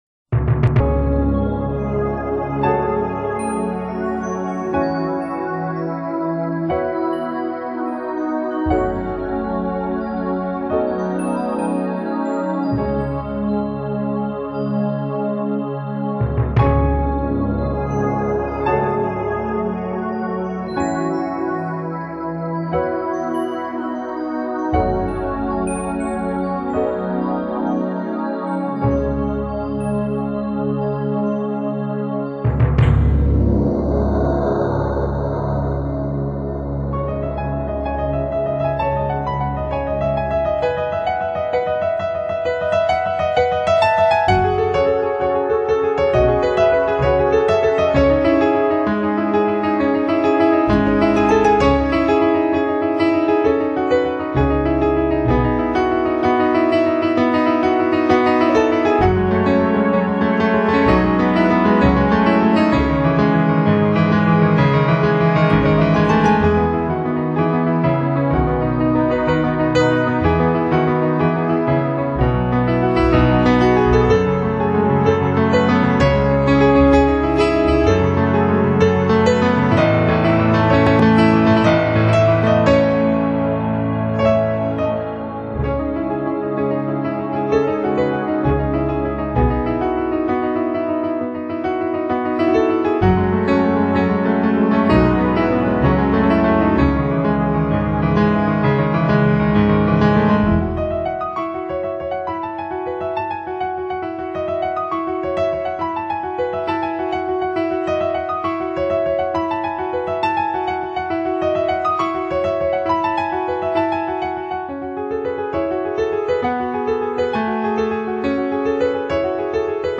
主题鲜明的钢琴演奏专辑，琢磨出钢琴静谧、激情的双面美感